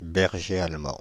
ÄäntäminenParis:
• IPA: [bɛʁ.ʒe al.mɑ̃]